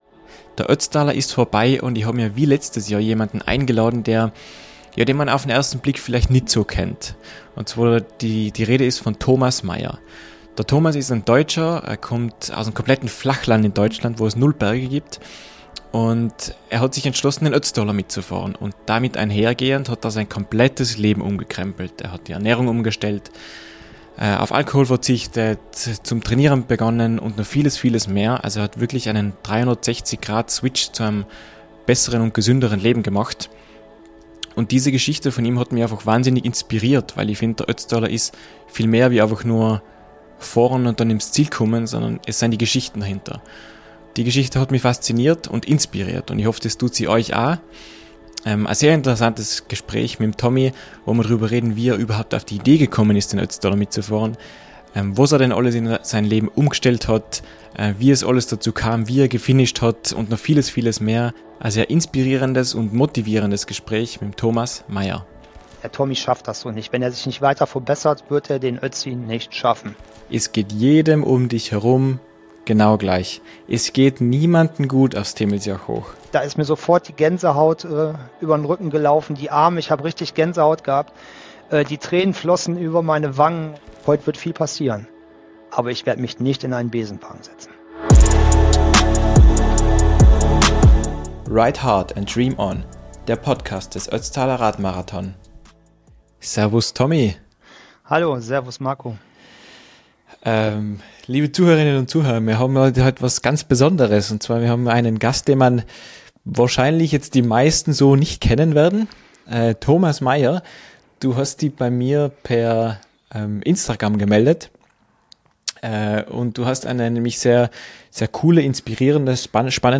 Den Traum hat er sich erfüllt - doch was steckt dahinter? EIn inspirierendes Gespräch